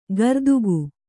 ♪ gardugu